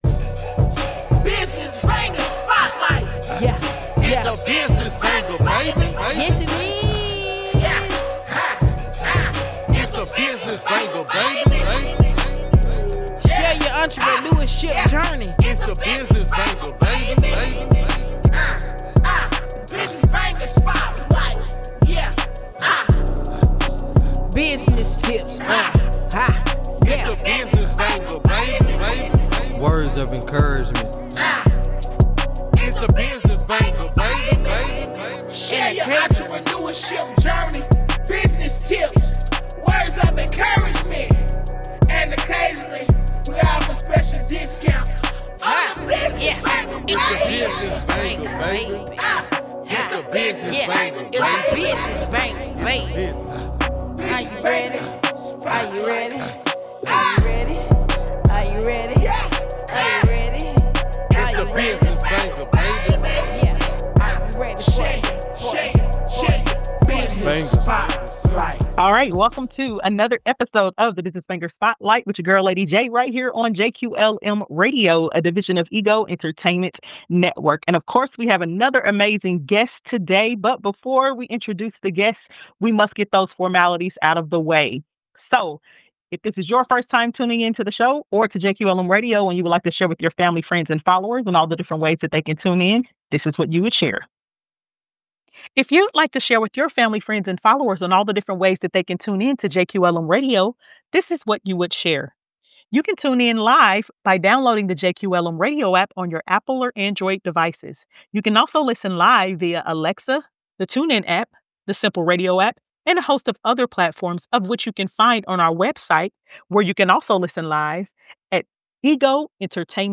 Additionally, guests get to participate in a segment called Business Gone Wild!